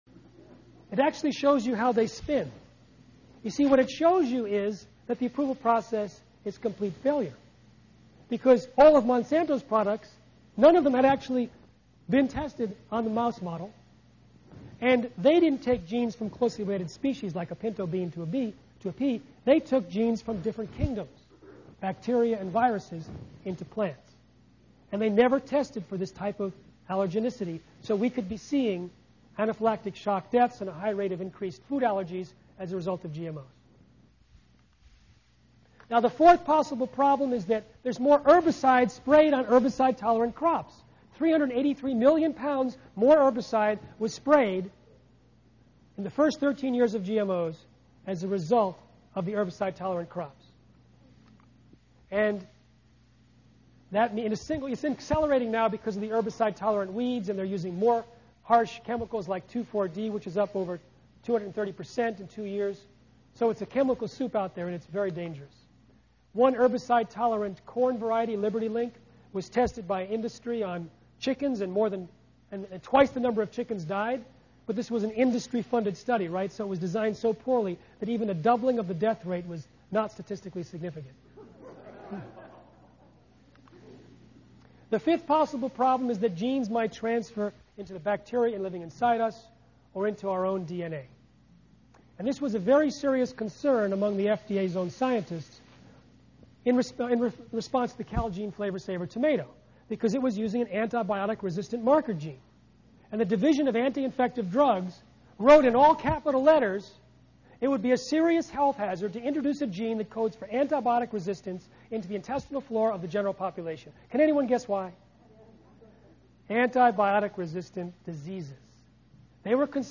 The Health Dangers of Genetically Modified Foods given Thursday, April 29th in Dalby Hall on the M.U.M. Campus.